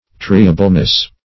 triableness - definition of triableness - synonyms, pronunciation, spelling from Free Dictionary
Triableness \Tri"a*ble*ness\, n. Quality or state of being triable.